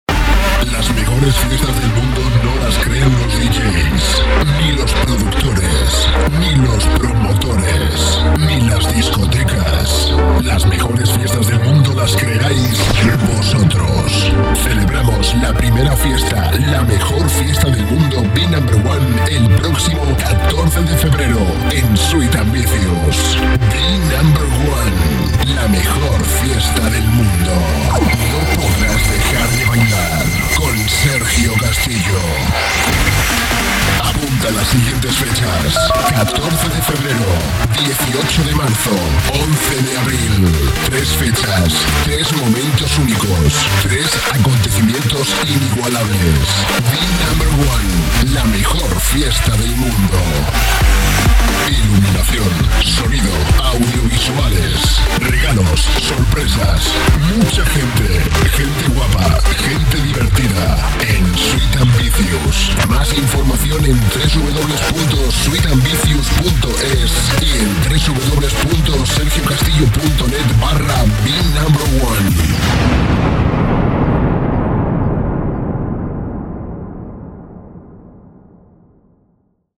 CUÑAS PUBLICITARIAS